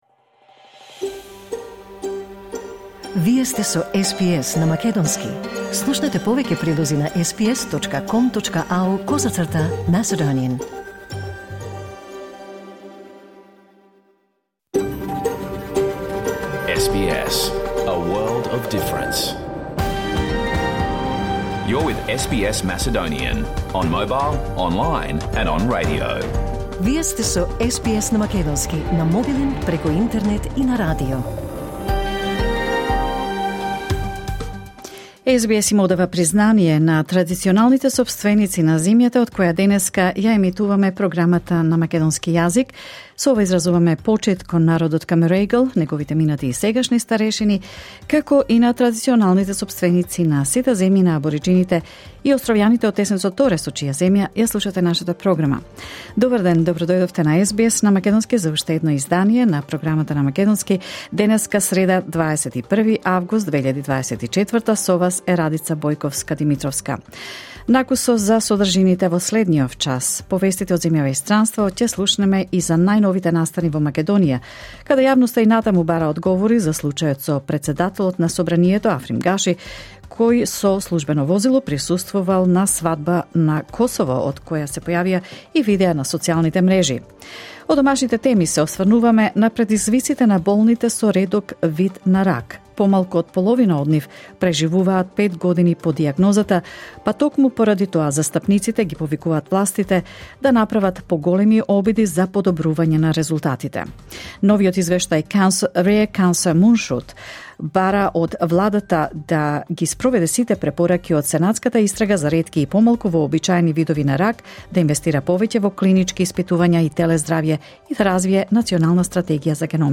SBS Program Live on Air 21 August 2024